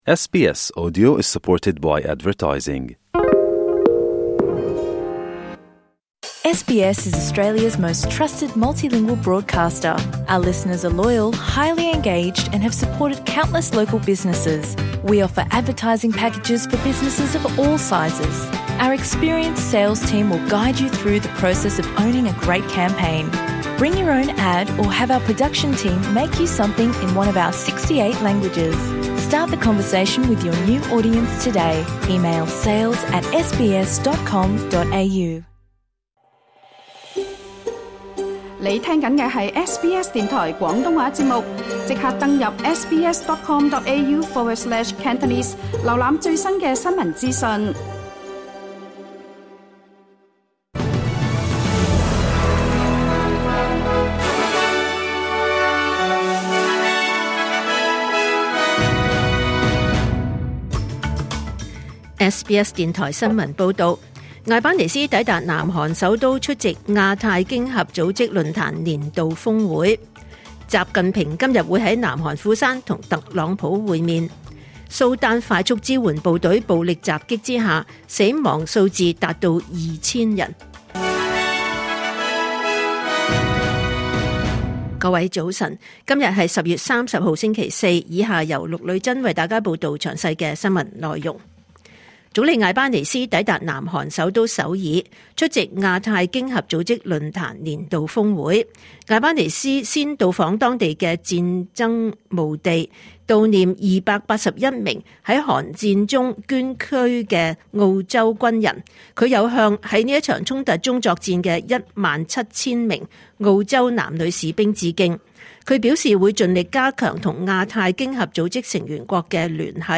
2025年10月30日SBS廣東話節目九點半新聞報道。